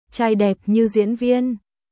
Trai đẹp như diễn viên俳優みたいにイケメンチャーイ・デップ・ニュー・ジエン・ヴィエン🔊